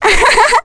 Ophelia-vox-Happy2.wav